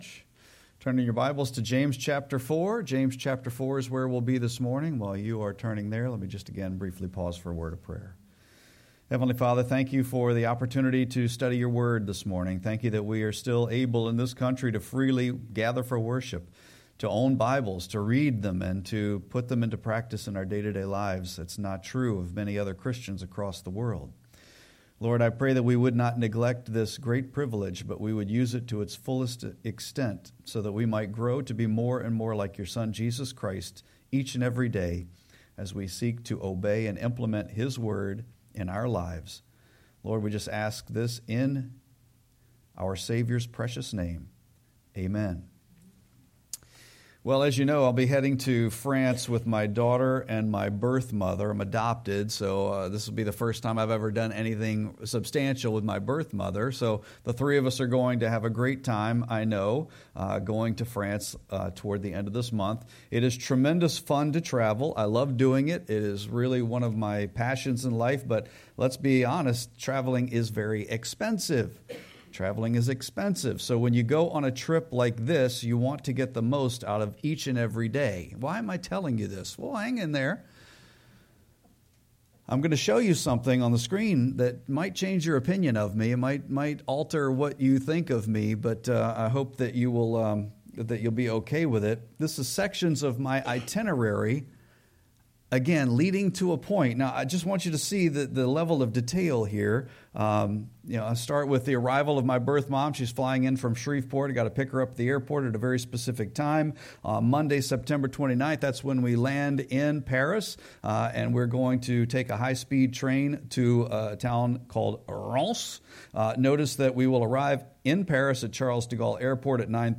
Sermon-9-7-25.mp3